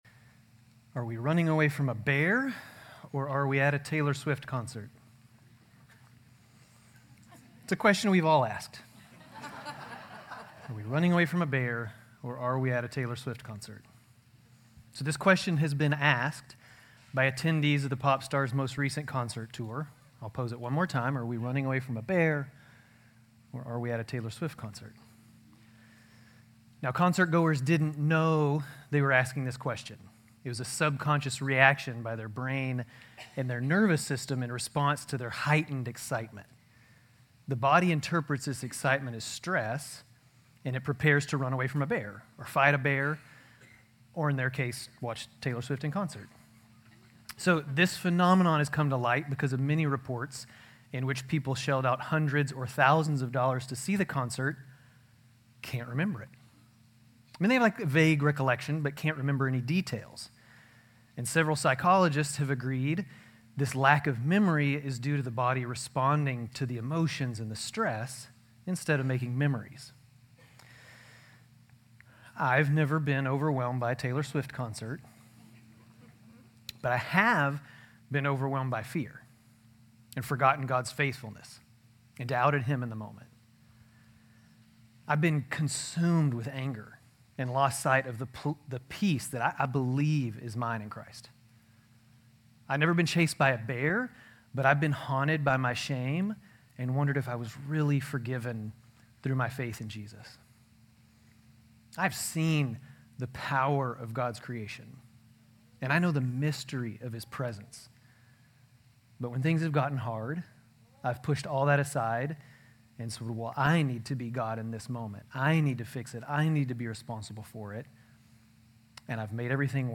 GCC-Lindale-June-18-Sermon.mp3